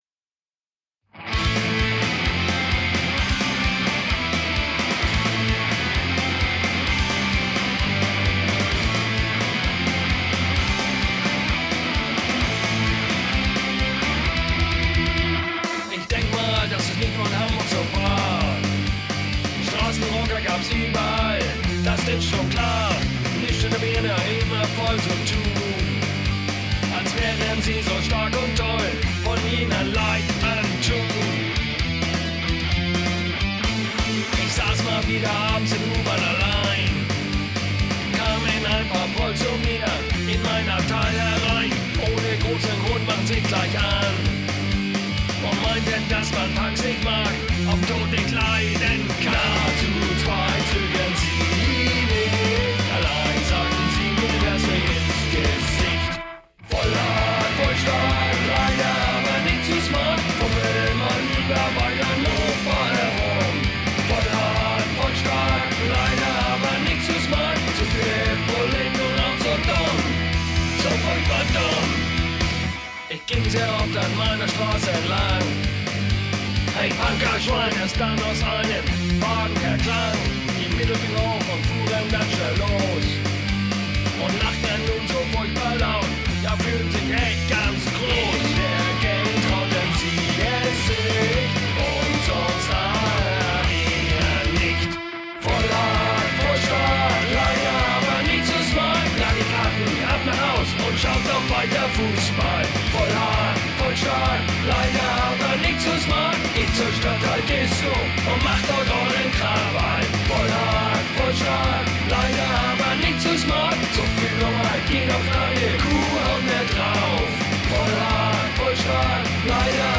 low quality web version
Lead Vocals
Doom Bass
Death Drums